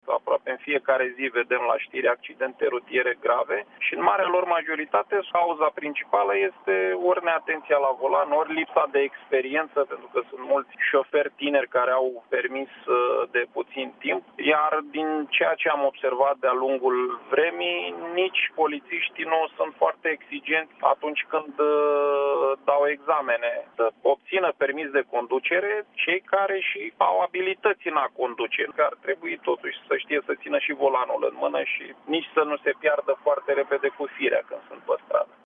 În opinia sa, cei care vor să obțină permisul ar trebui să dea proba teoretică, condus în poligon și traseu pe timp de zi, dar și pe timp de noapte. Florin Gheorghe, Deputat PSD.
15-iunie-17-Voce-deputat-Modificare-examen-auto.mp3